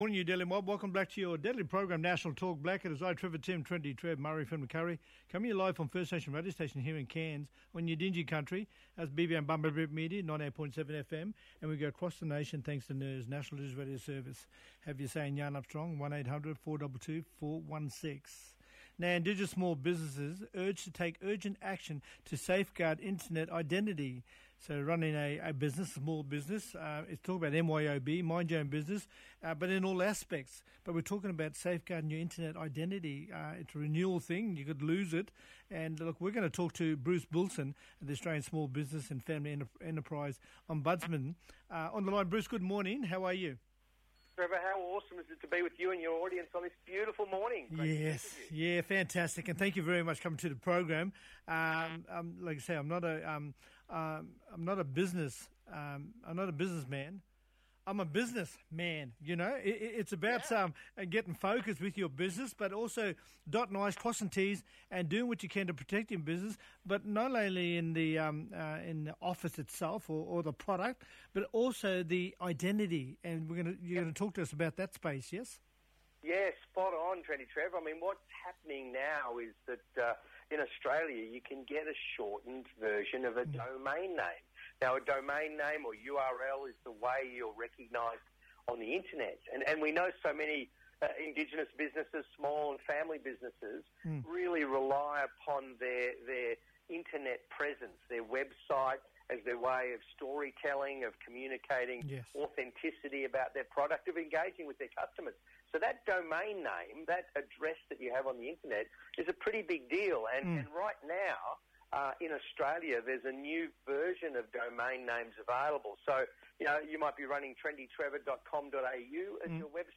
Bruce Billson, Australian Small Business and Family Enterprise Ombudsman talking about Indigenous small businesses urged to take urgent action to safeguard internet identity. Bruce has implored Indigenous small businesses to take urgent action to safeguard their brand and identity on the internet or risk seeing impersonators, web-name ‘campers’ or cyber criminals take up domain names just like theirs.